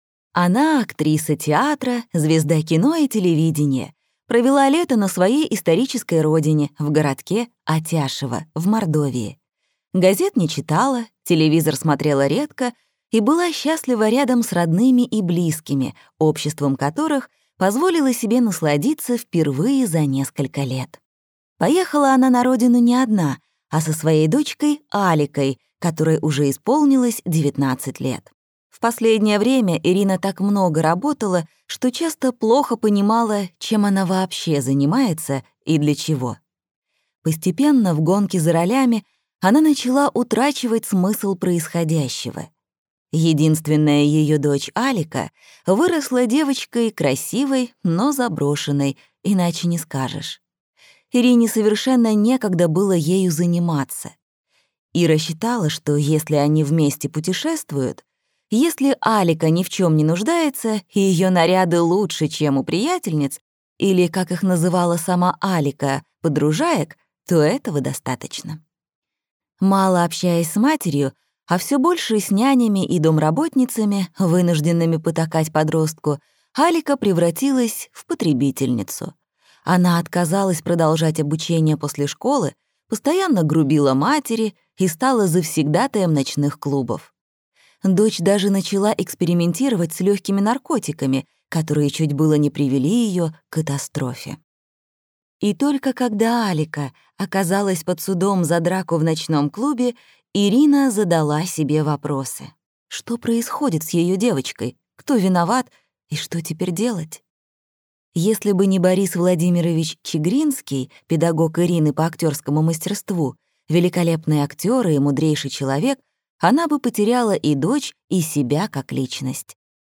Аудиокнига Синдром Атяшево | Библиотека аудиокниг